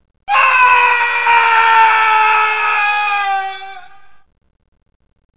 Kermis geluid Aaaaaaii…
Categorie: Geluidseffecten
Beschrijving: Breng de Tilburgse Kermis naar je oren met het iconische "Aaaaaaii..." geluid! Dit typische kermisgeluid is nu beschikbaar als mp3-download.
kermis-geluid-aaaaaaii-nl-www_tiengdong_com.mp3